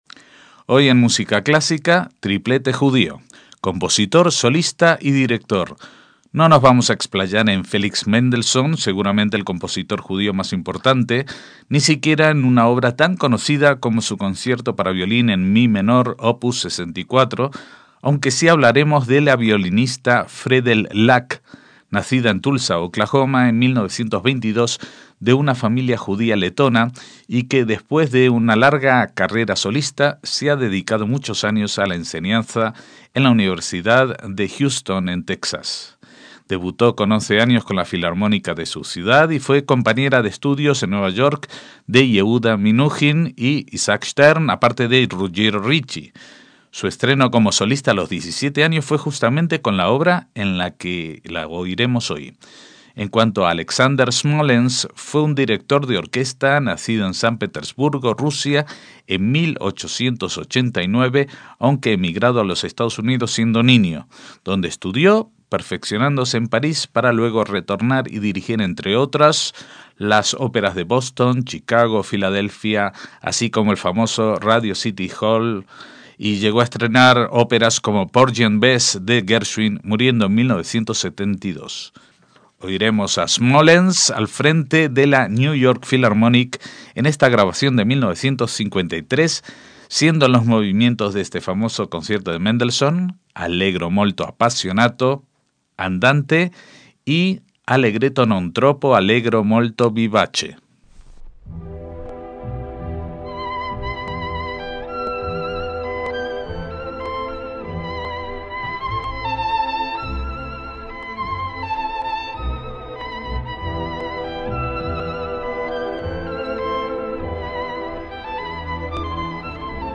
MÚSICA CLÁSICA - El Concierto de violín de Mendelssohn es una de las piezas más reconocibles del repertorio clásico romántico. En este caso singular, tanto el autor, como la solista y el director de la orquesta son judíos.
Por último, la violinista Fredell Lack (1922 - 2017) nació en Tulsa, Oklahoma, y a los 17 años debutó como s